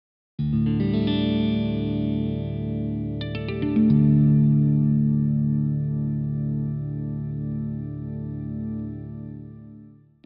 D-A-D-G-A-D
Resembles a Standard-tuned ‘0-2-2-2-0-0’ Esus4 shape in terms of interval structure. Neither major nor minor, DADGAD’s ambiguous open-chord resonance offers up incredible versatility, ideal for exploring the musical variety of many global traditions.